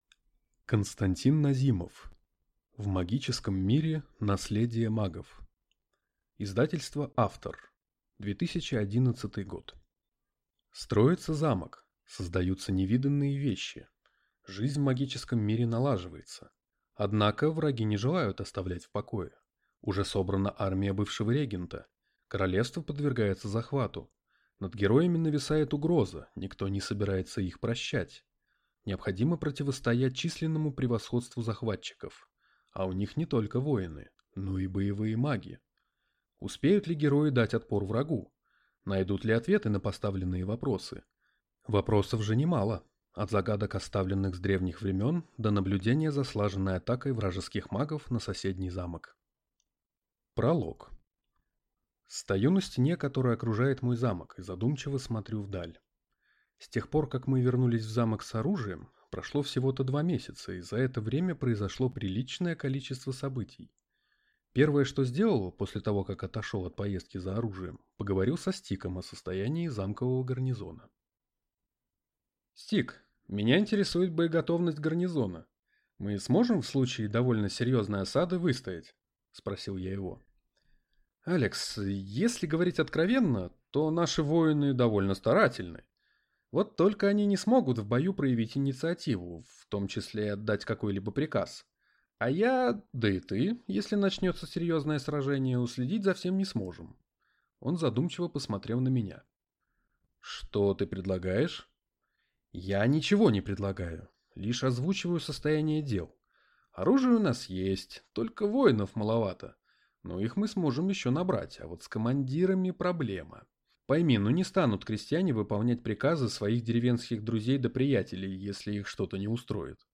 Аудиокнига В магическом мире: наследие магов | Библиотека аудиокниг